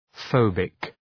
Προφορά
{‘fəʋbık}